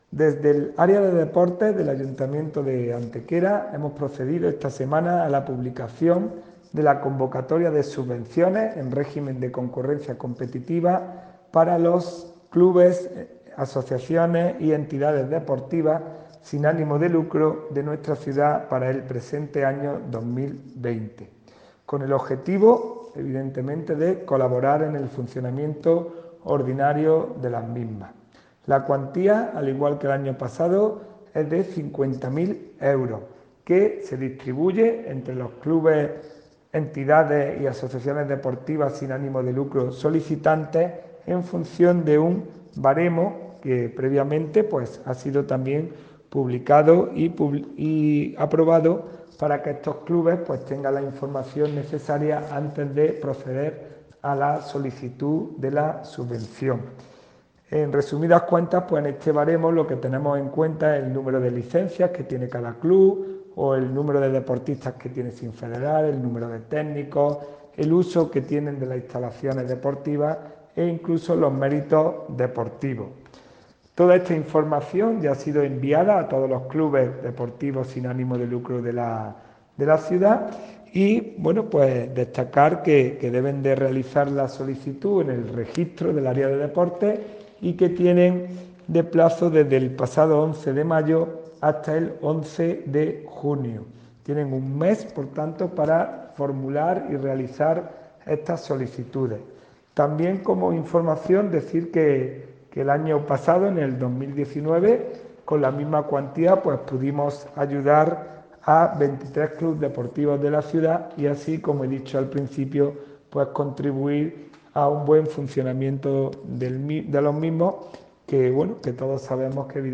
El teniente de alcalde delegado de Deportes, Juan Rosas, informa de la apertura del plazo de solicitud de una nueva convocatoria de subvenciones destinadas a clubes, entidades o colectivos deportivos sin ánimo de lucro que tengan establecida su sede en nuestro municipio. 50.000 euros se ofrecerán con el objetivo de colaborar con el funcionamiento habitual de los mismos.
Cortes de voz